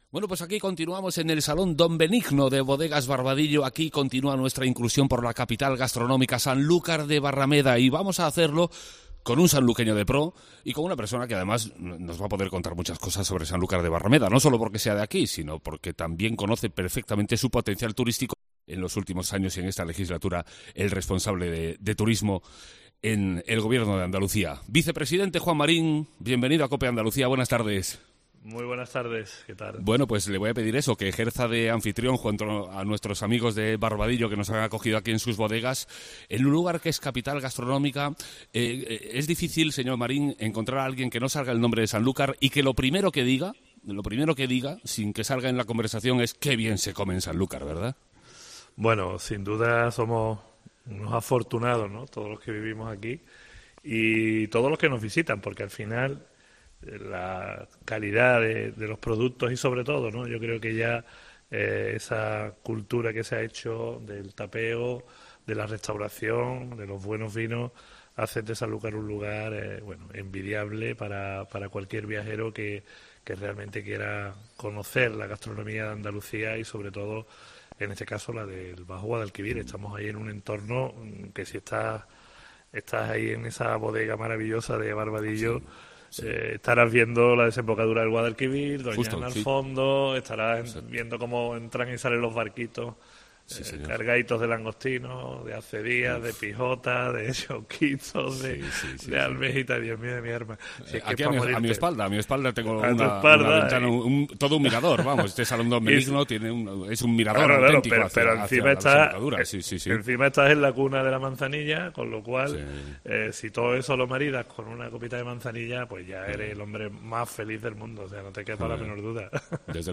Juan Marín, Vicepresidente y Consejero de Turismo de la Junta de Andalucía ha estado en el programa especial de COPE Andalucía con motivo de la capitalidad gastronómica de Sanlúcar de Barrameda. Como buen sanluqueño habla de la magnífica gastronomía de este municipio, el pescado, mariscos, la manzanilla y las verduras y hortalizas de una ciudad bañada por el Guadalquivir.